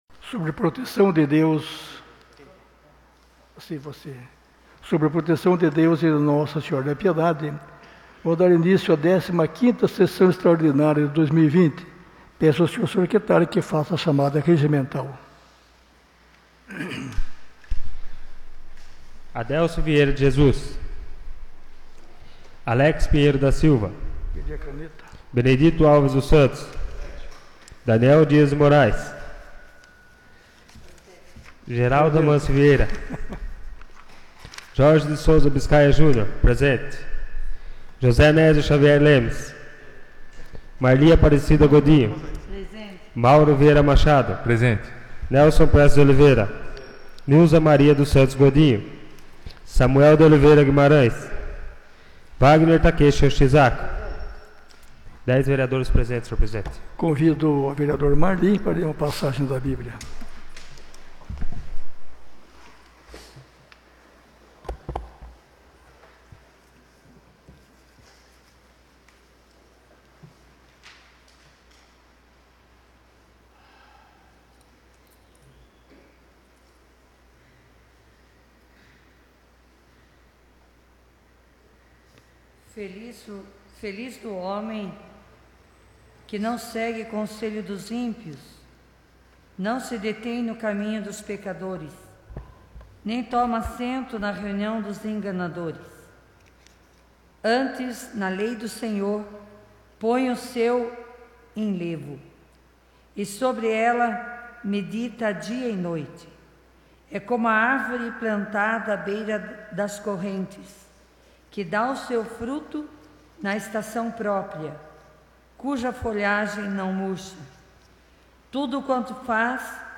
15ª Sessão Extraordinária de 2020 — Câmara Municipal de Piedade